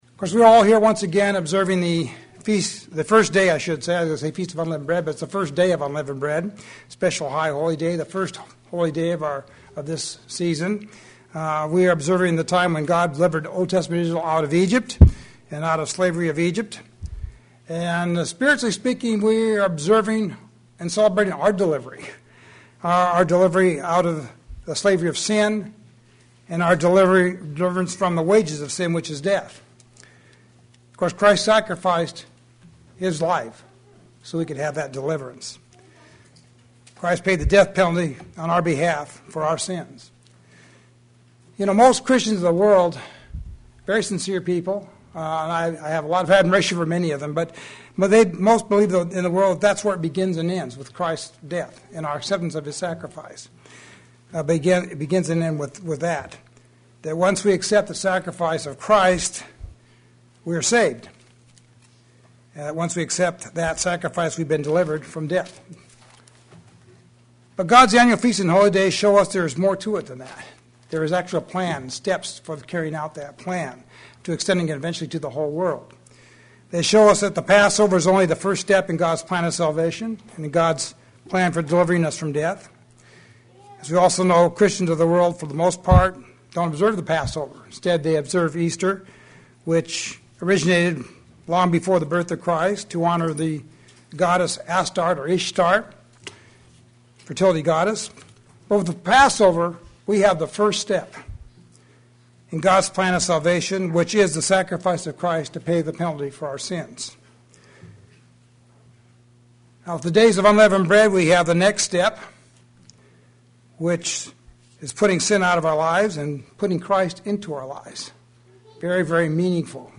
What sacrifices must we make to be sermon Transcript This transcript was generated by AI and may contain errors.